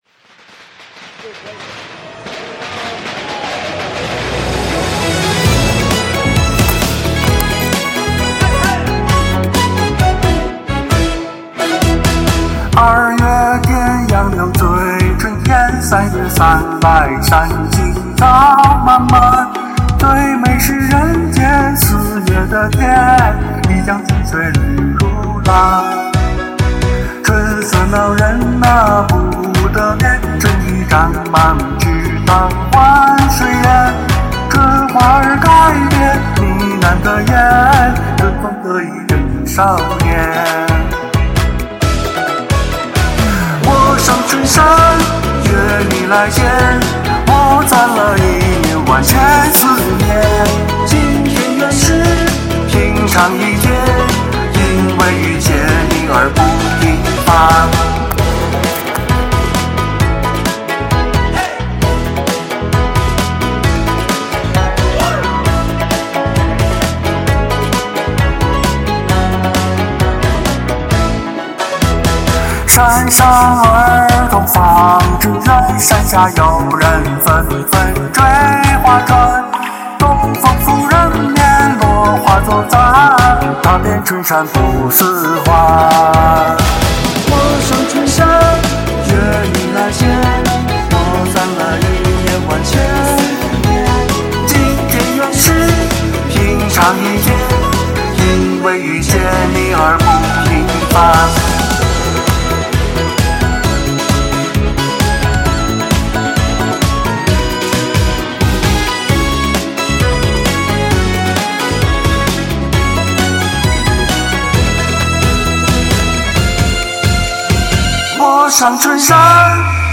“二月天杨柳醉春烟” 句：喉转柔波，似东风拂柳丝，颤音处如露滴新叶，将“醉”字酿出三春醇酒；
“三月三来山青草漫漫” 句：气息绵长若山岚舒卷，“漫”字尾音铺展如碧草连天，听者恍见青峰叠翠；
“灿烂的笑啊似今年” 句：笑靥融于声线，高音如旭日破云，灼灼生暖意，尽释丙午马年蓬勃之气。